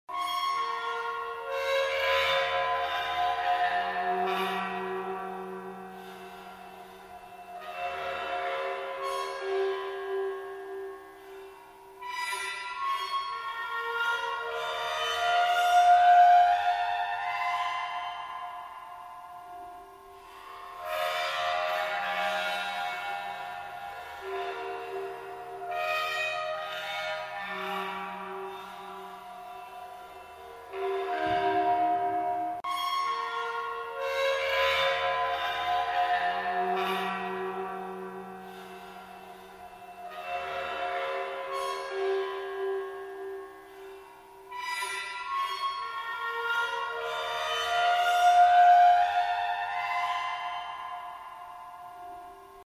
Звуки металла
Жуткие скрипы металла